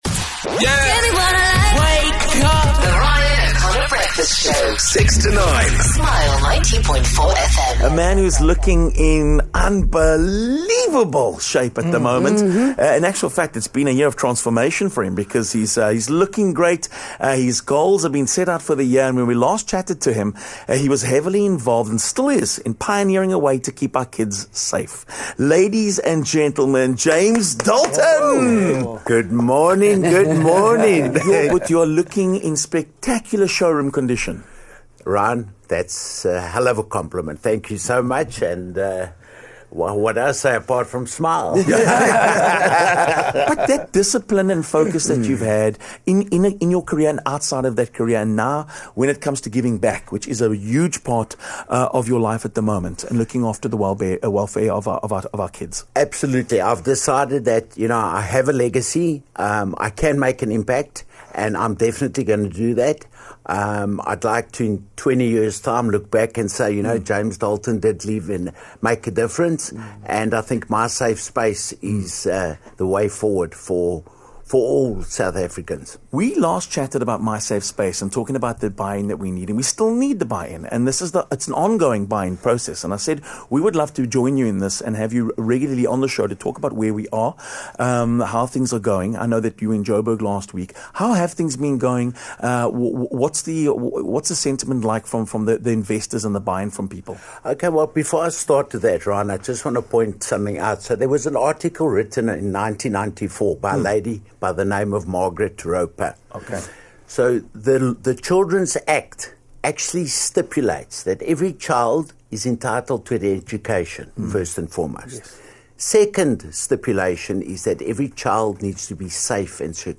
Former Springbok superstar James Dalton popped in for a chat.